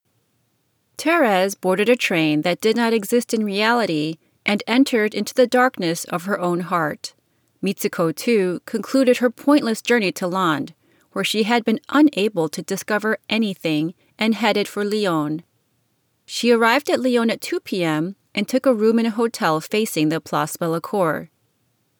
Side note. I applied Audiobook Mastering and your clip easily passes ACX Conformance.
This is after removing the mosquitoes, AudioBook Mastered, and I removed some of the harsh SS sounds with the DeSibilator.